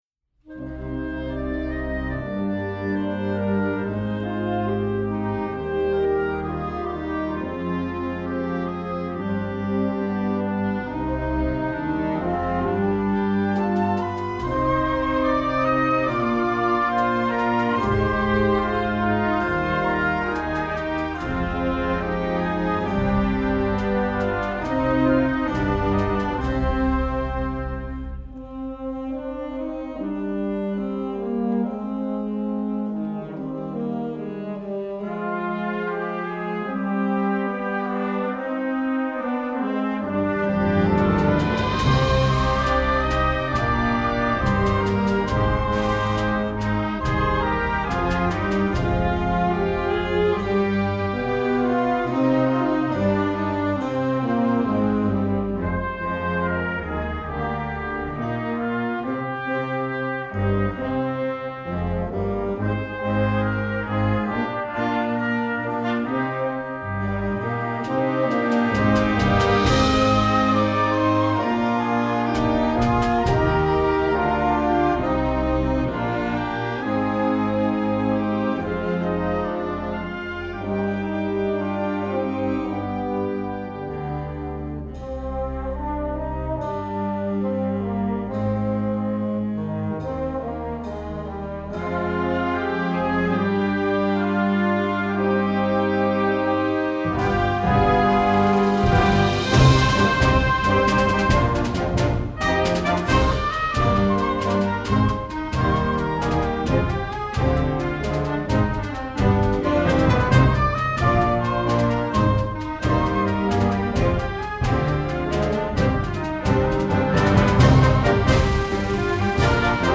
Gattung: Jugendwerk
3:07 Minuten Besetzung: Blasorchester PDF